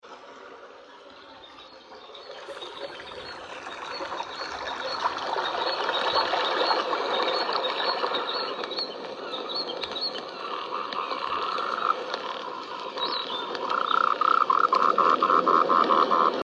Humidifier with bluetooth speaker Thanks to this white noise humidifier with rain and soothing sounds, she can sleep faster and better—perfect for those with insomnia, anxiety, and stress